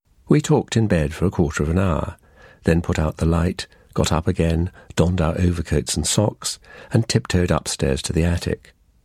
And here are some examples from real native speakers of English:
/tɔːkt/ /ˈkɔːtə/ /gɒt/ /dɒnd/ /sɒks/